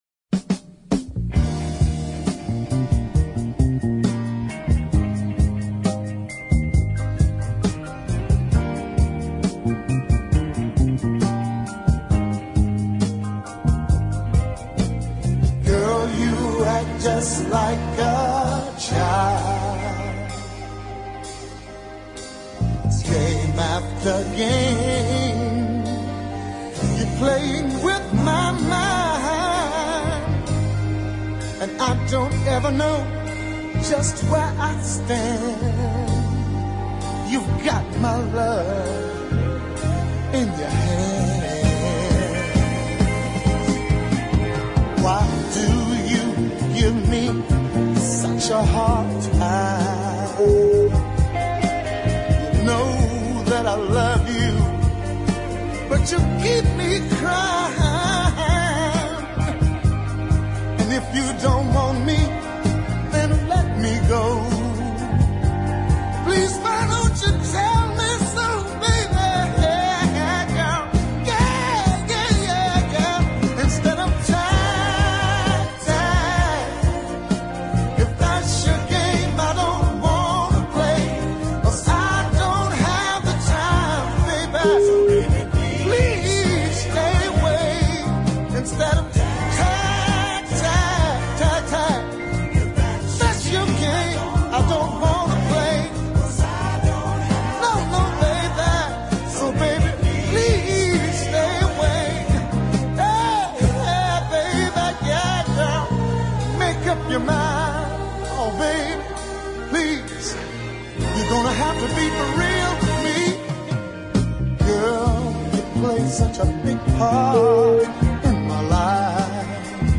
This lovely melodic ballad
subtle arrangement